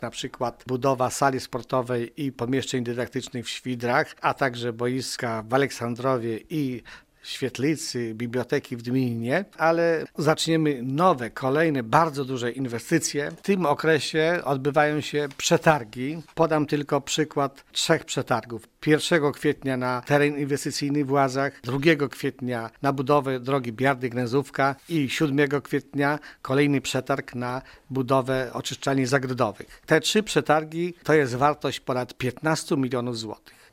Zastępca Wójta Wiktor Osik zaznacza, że jedne inwestycje będą się dopiero rozpoczynały, a inne będą kontynuowane, bo ich wykonanie zaplanowano na kilka lat: